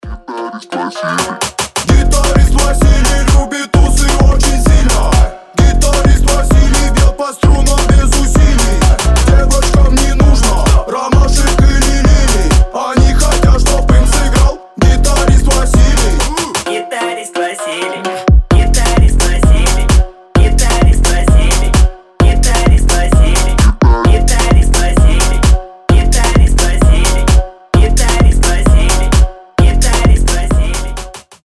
веселые
басы
смешные